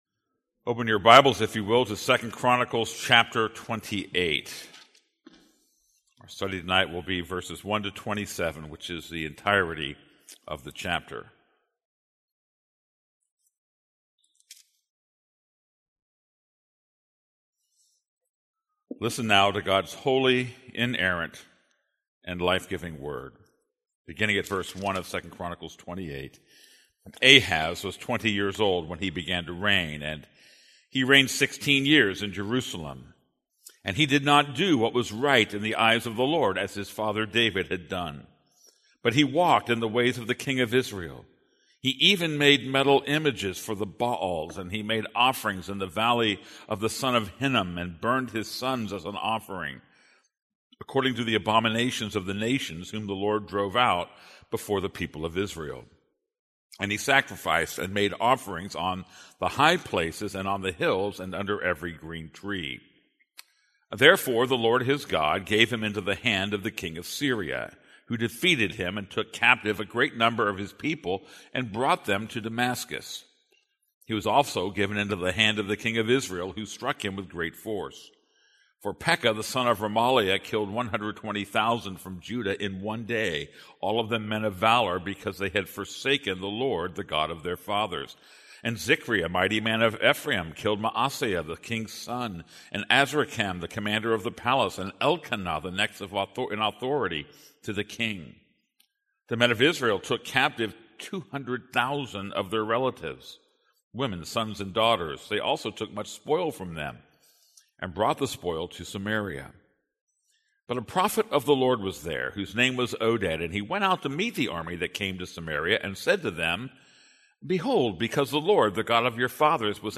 This is a sermon on 2 Chronicles 28:1-27.